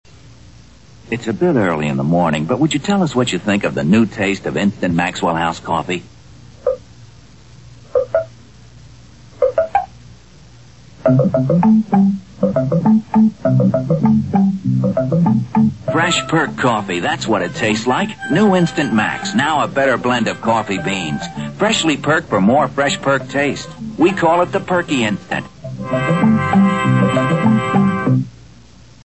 Old Maxwell House Commercial (“instant fresh perked taste” !!)